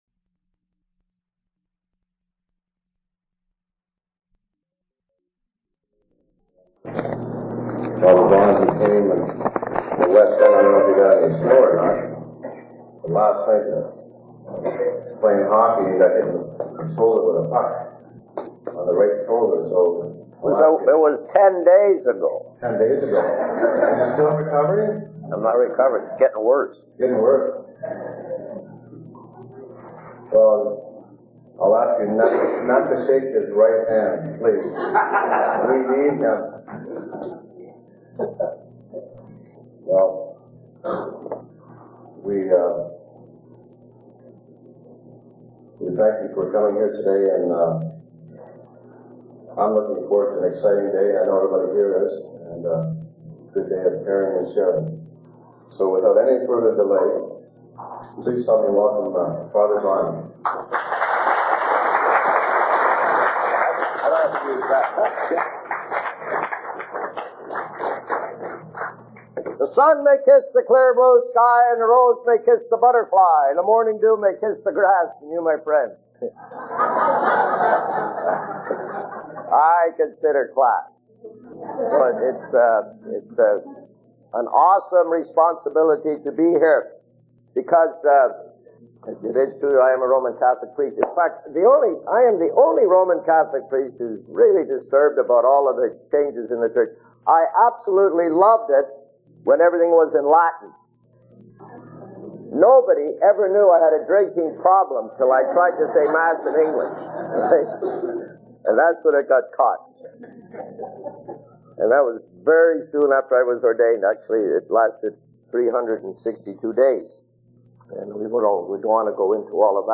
Speaker Tape